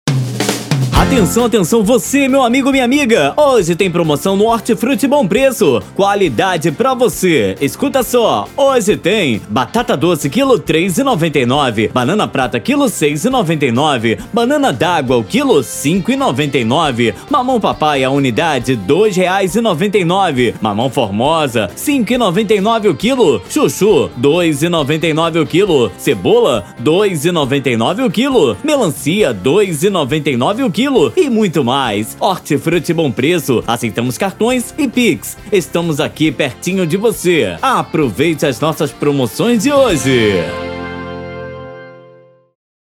Todos os áudios são produzidos e renderizados na mais alta qualidade e convertidos para o formato que melhor atender suas necessidades.